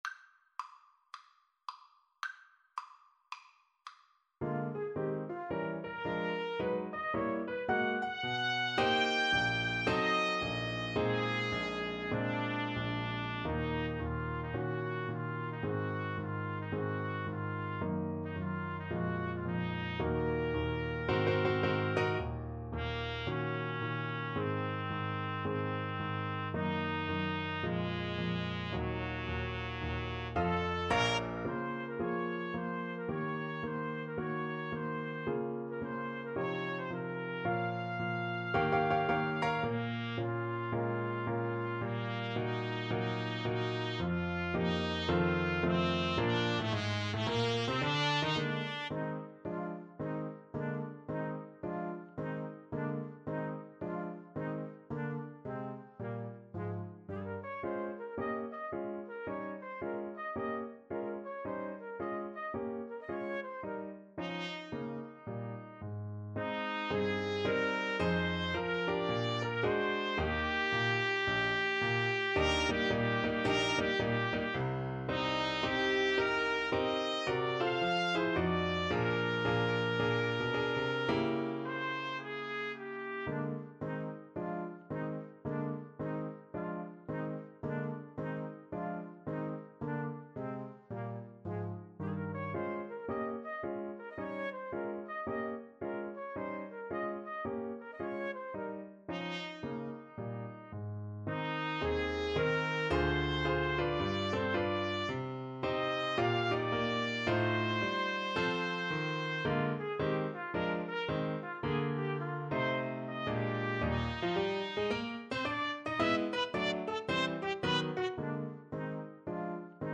4/4 (View more 4/4 Music)
Moderato =110 swung